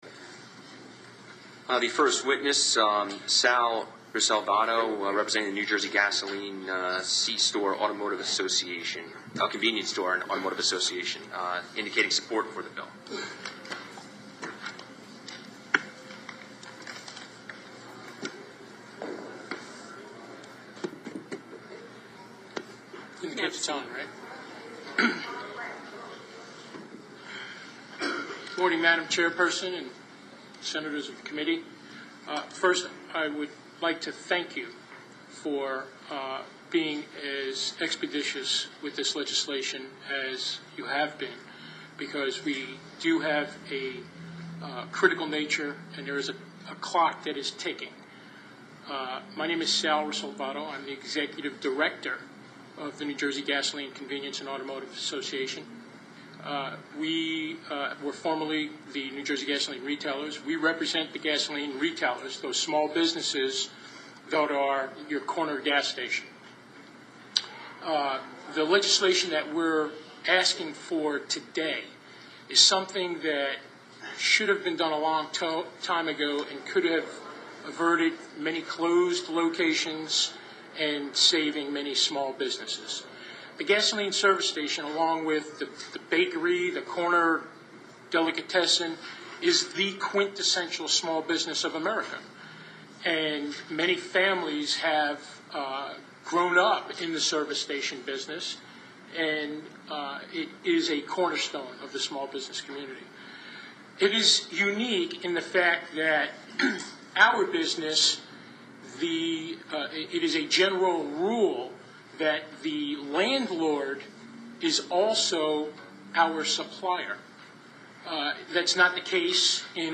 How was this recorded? It was introduced on February 9, 2009 and was heard by the Senate Commerce Committee on February 26th.